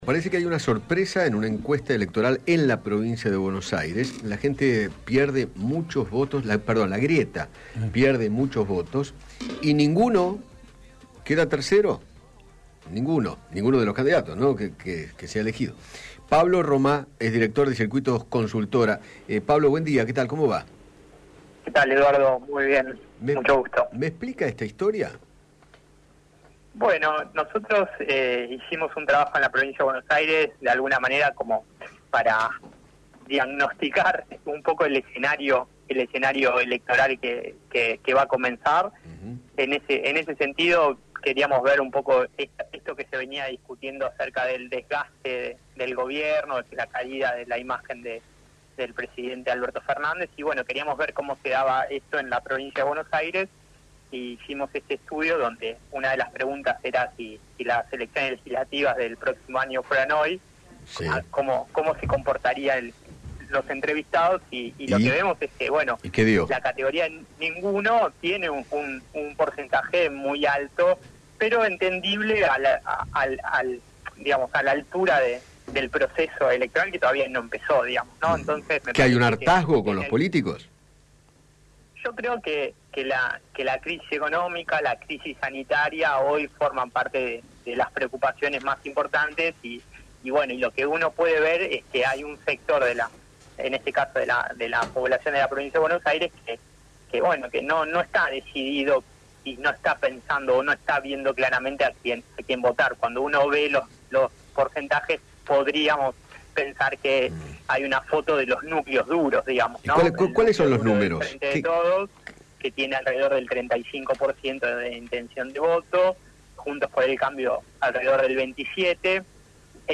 Eduardo Feinmann conversó con